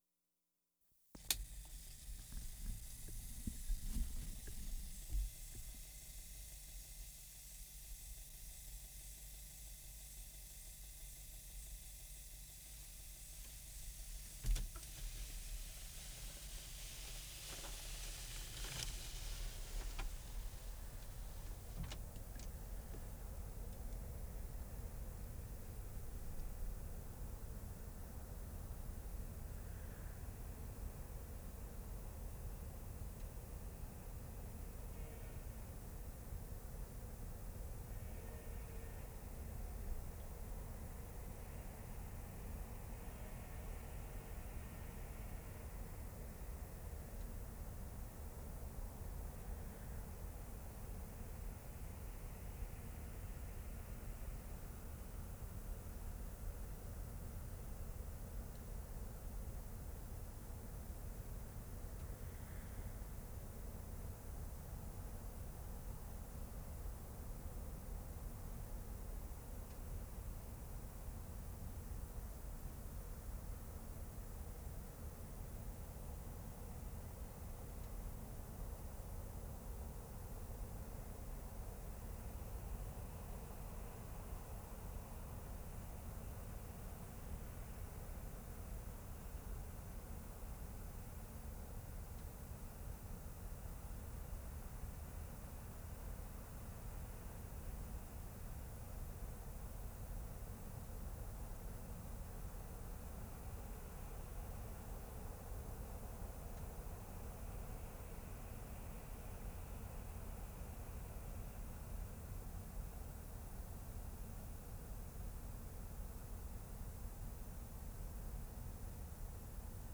WORLD SOUNDSCAPE PROJECT TAPE LIBRARY
1. Opens inside van. Window rolls down.
0'45" distant train whistle.
General city rumble, little foreground sound. On headphones, there is quite a bit more spatial depth perceived than over speakers.